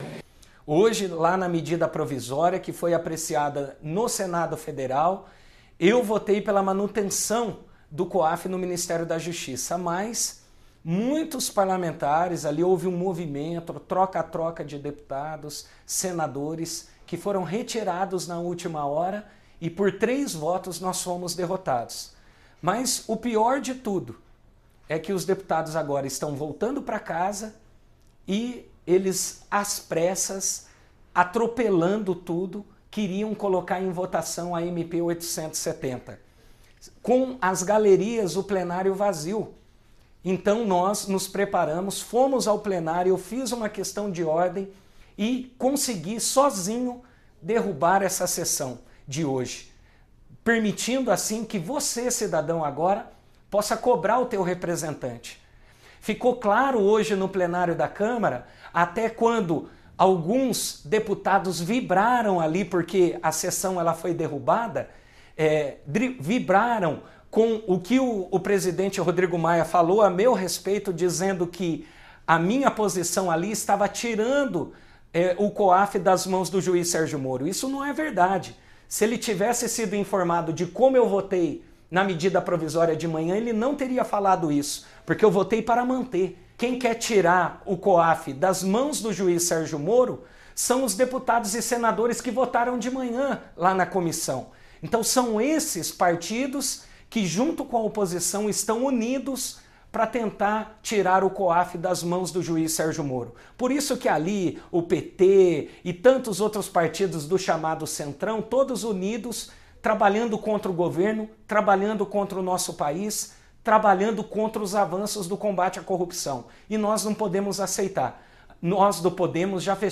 Em pronunciamento em suas redes sociais, Diego Garcia também esclareceu a manobra feita pelo “centrão” para a ida do Coaf ao Ministério da Economia.
Declaração-Dep-Fed-Diego-Garcia_Coaf-1.mp3